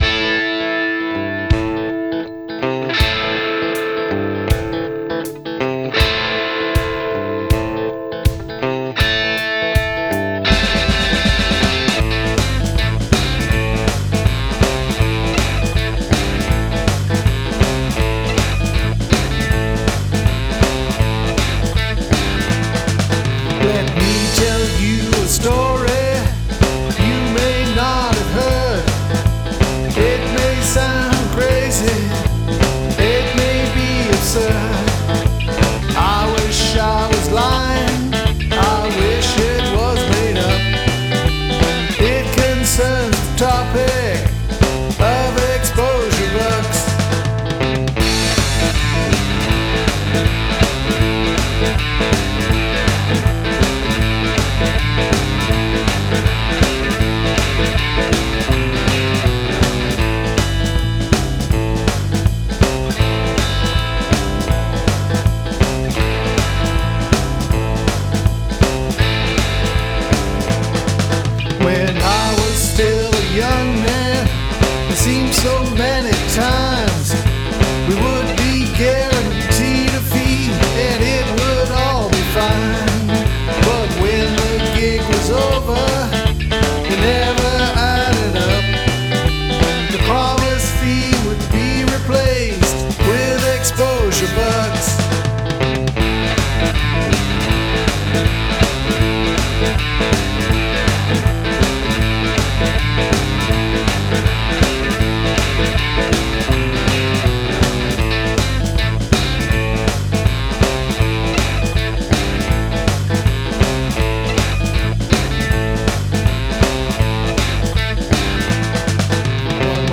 Similar feel and dynamics for sure!
boogie rhythm